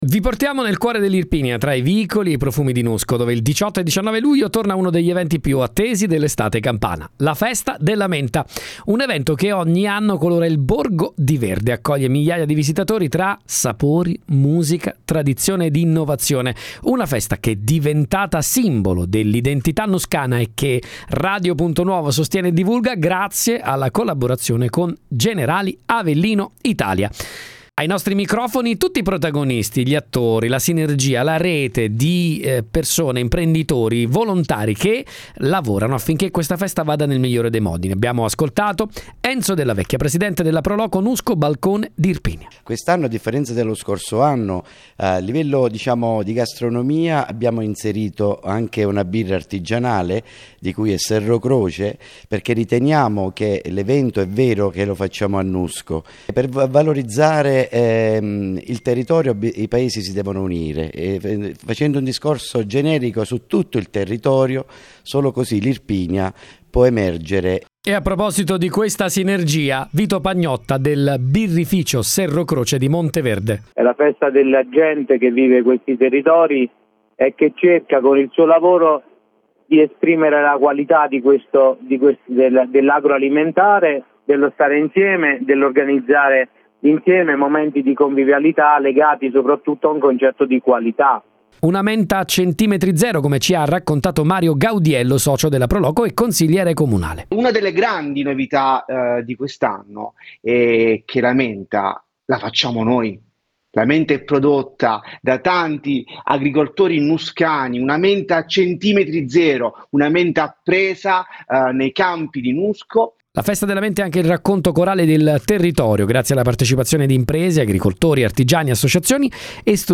Ai nostri microfoni, i protagonisti della festa: volontari, imprenditori, agricoltori, amministratori e albergatori, tutti uniti da uno spirito di collaborazione